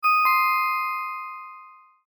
알림음